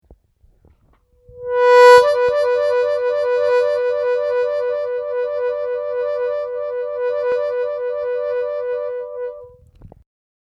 Трель -3-4